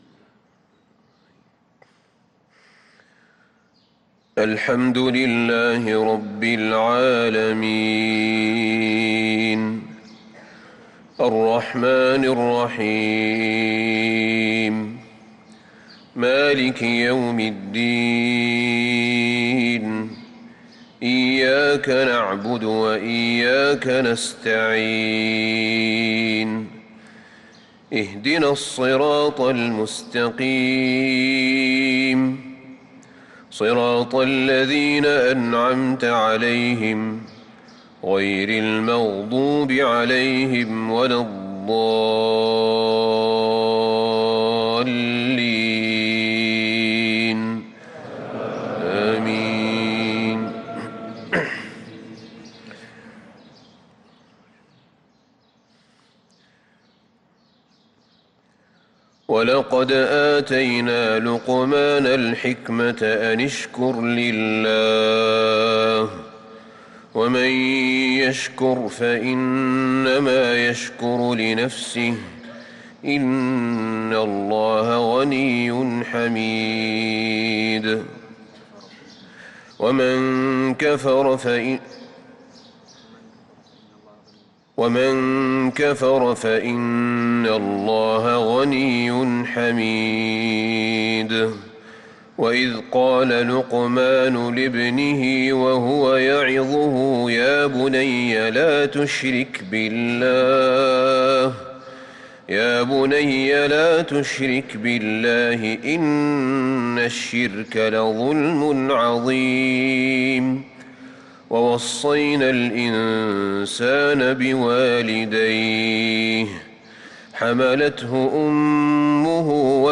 صلاة الفجر للقارئ أحمد بن طالب حميد 4 رجب 1445 هـ